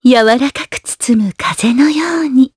Yuria-Vox_Skill5_jp_b.wav